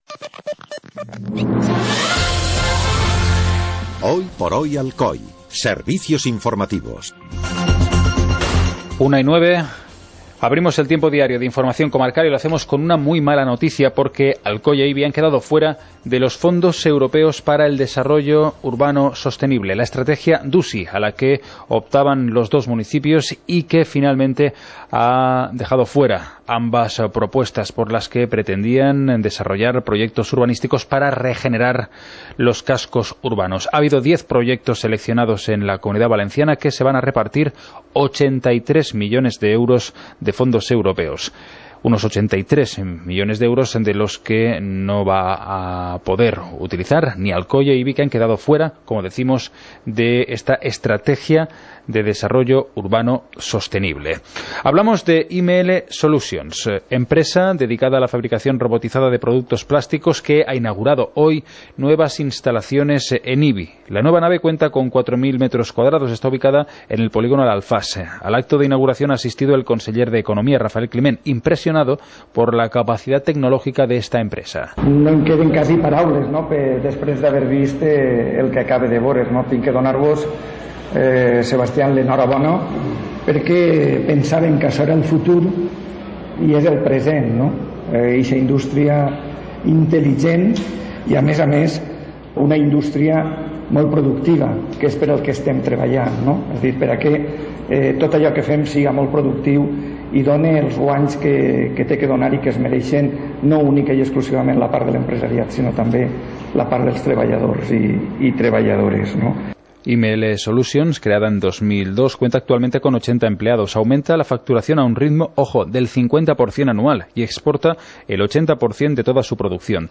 Informativo comarcal - martes, 04 de octubre de 2016